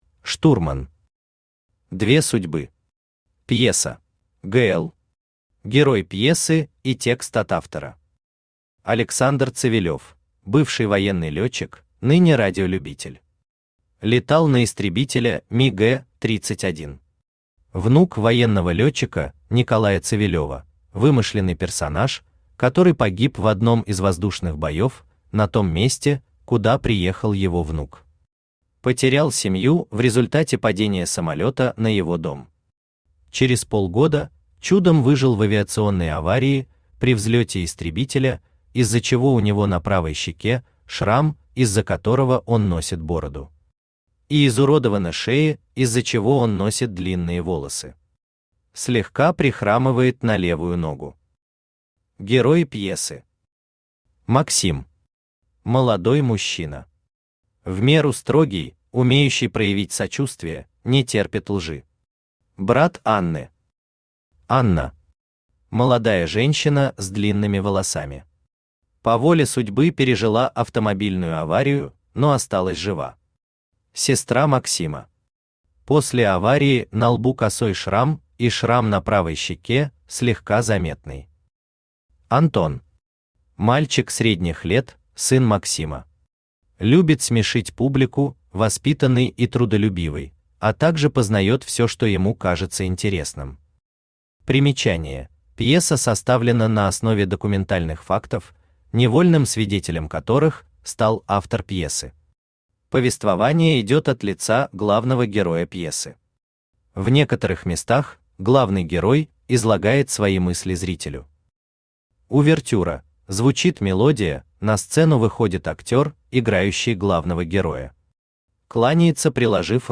Аудиокнига Две судьбы. Пьеса | Библиотека аудиокниг
Пьеса Автор Штурман Читает аудиокнигу Авточтец ЛитРес.